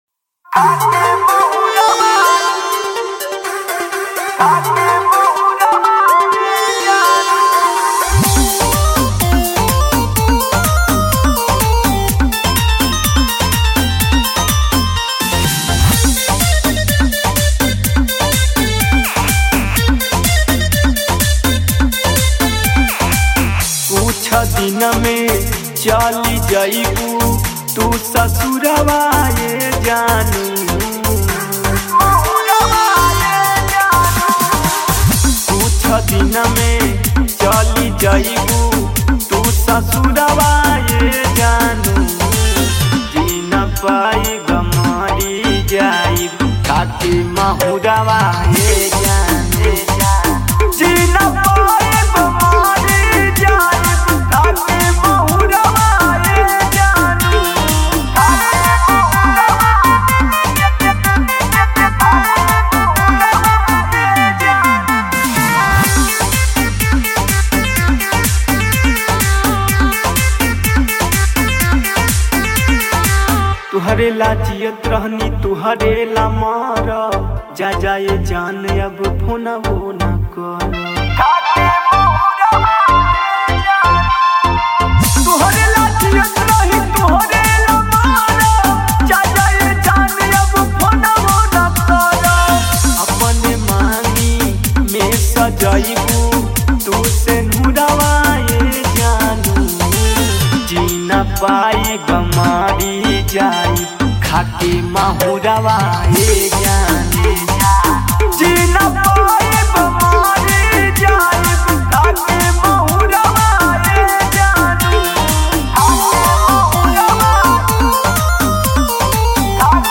bhojpuri song